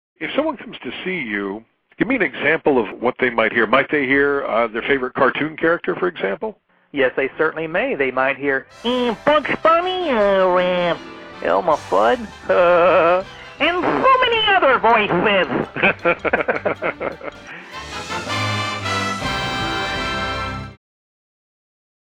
Hear Your Favorite Characters